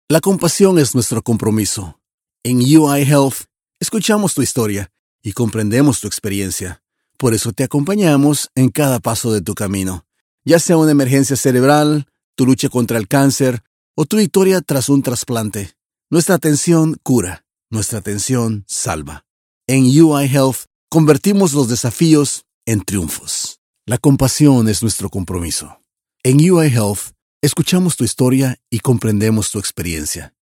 Bilingual clear voice, dynamic, positive, emotive, promotional, corporative, friendly.
My PRO STUDIO is equipped with SOURCE CONNECT STANDARD (available at an extra rate), PRO TOOLS, AKG 414 B/ULS mic, and AVID MBOX STUDIO.